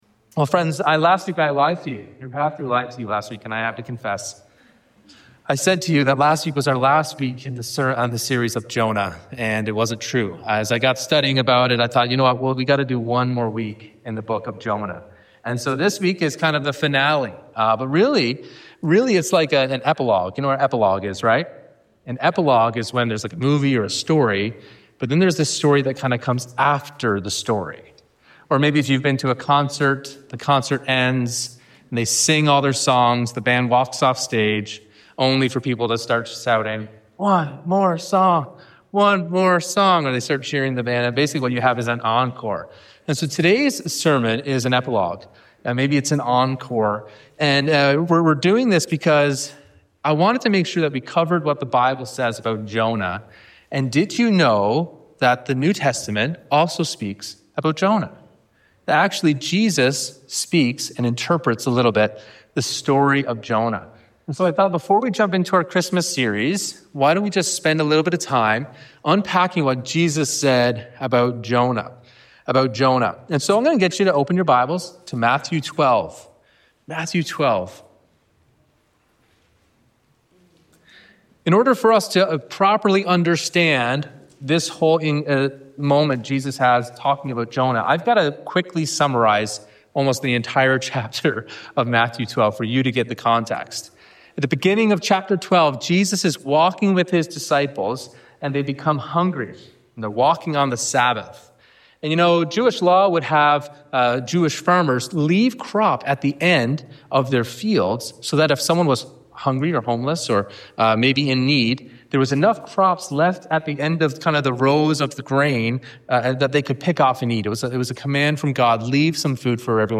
Sign of Jonah  Sermon.MP3